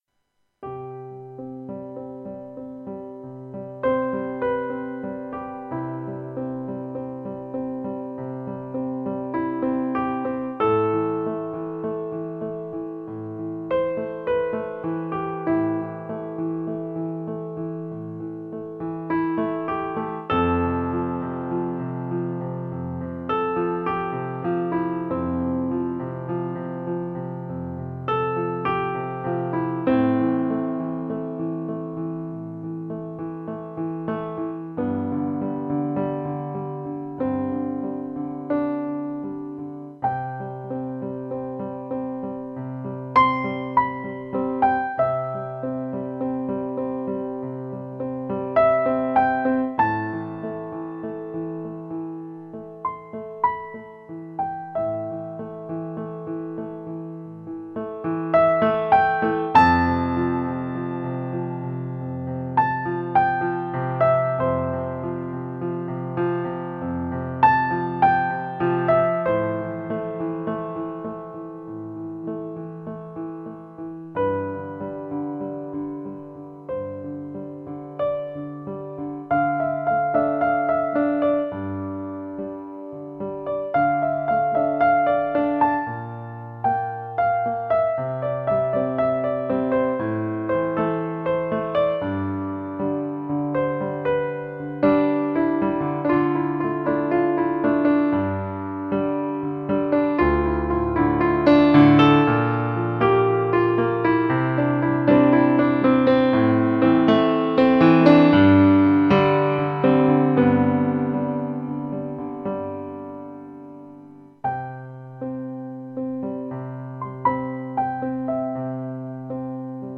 Žánr: Indie/Alternativa
Relaxační "easy listening" hudba na piano